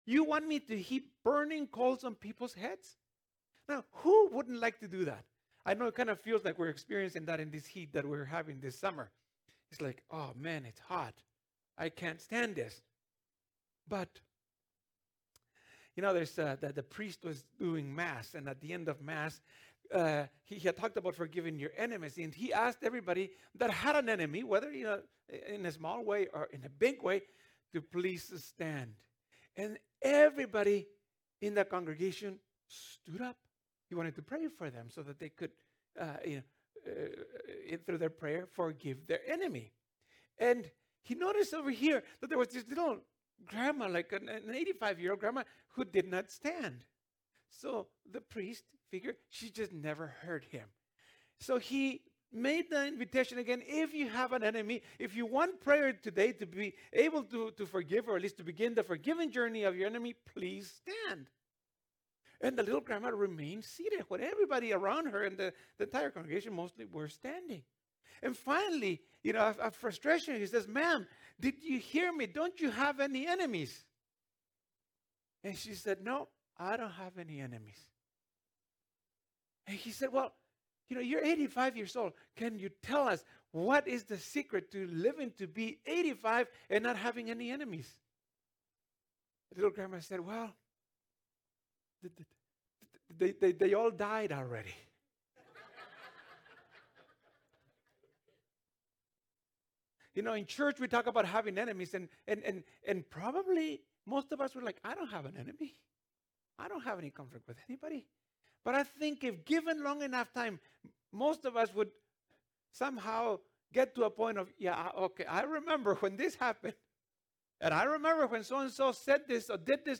This the second sermon in the You Want Me to do What? series.